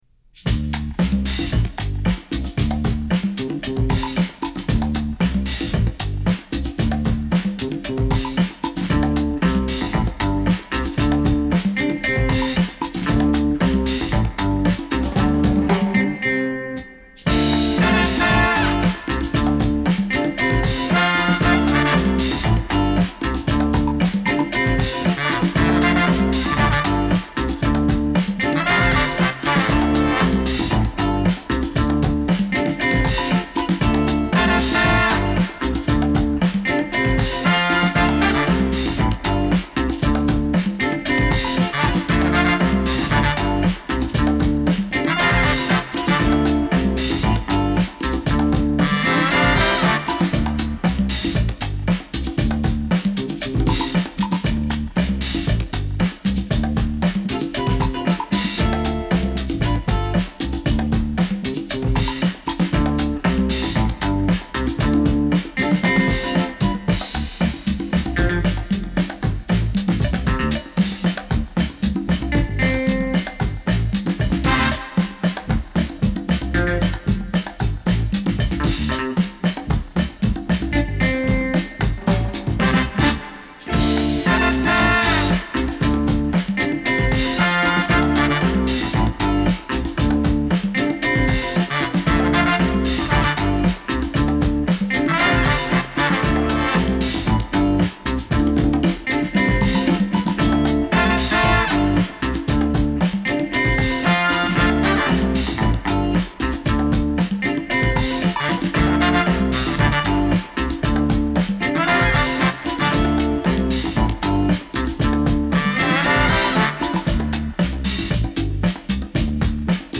Genre : Funk, Soul